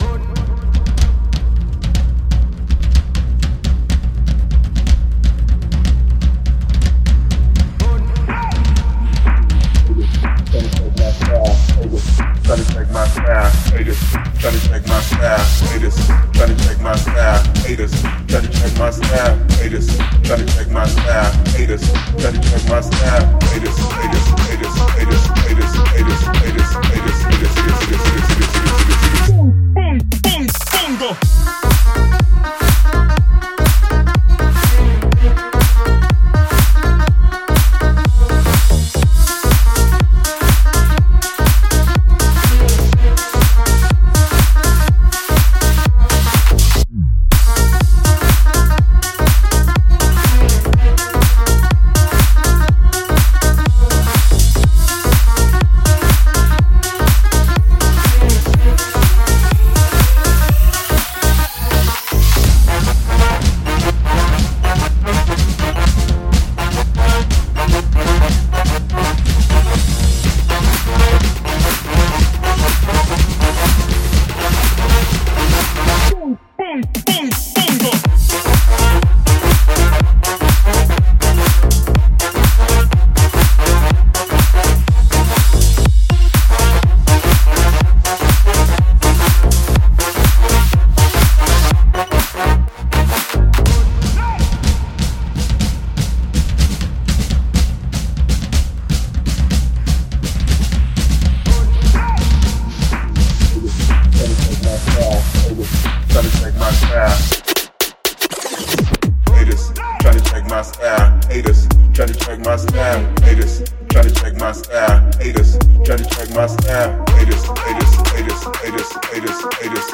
это энергичная композиция в жанре хип-хоп